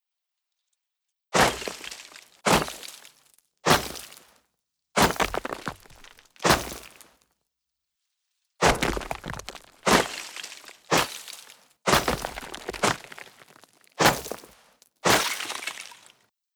Mining Ore Node Sound Effect Free Download
Mining Ore Node